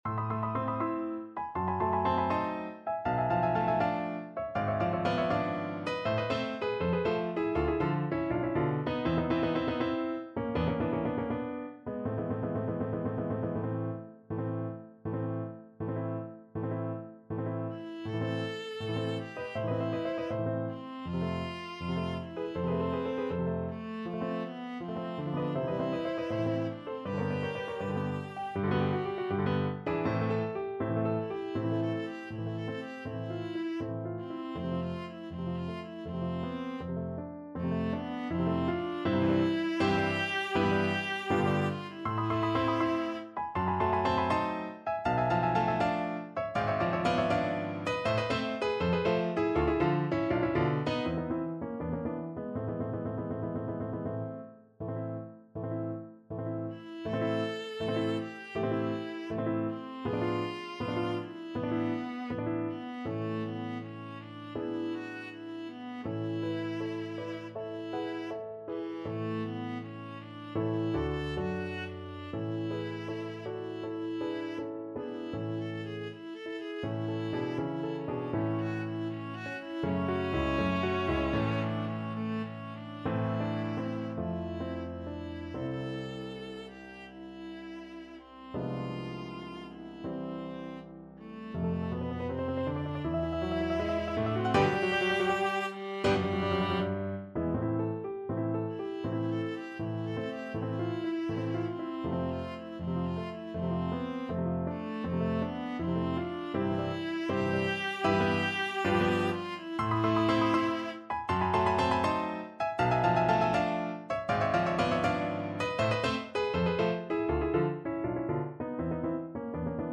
Viola
4/4 (View more 4/4 Music)
A minor (Sounding Pitch) (View more A minor Music for Viola )
~ = 100 Moderato =80
Classical (View more Classical Viola Music)